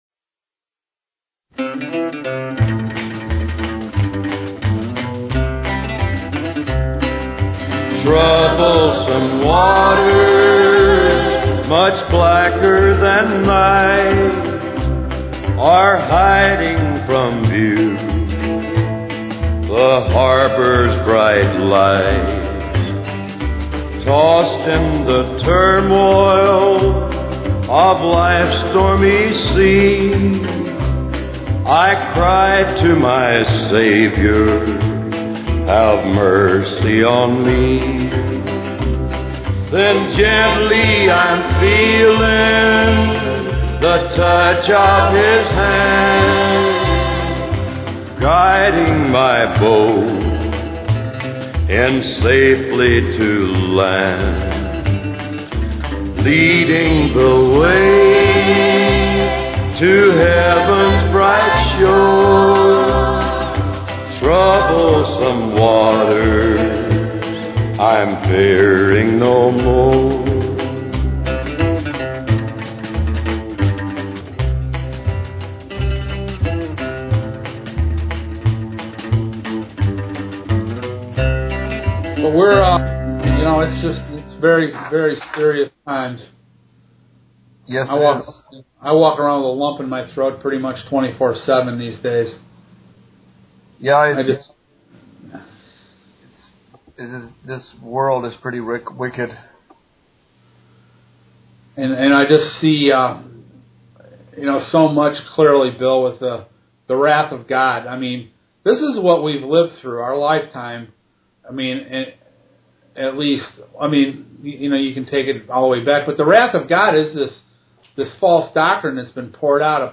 It is great listening to a couple of guys talk about the Word.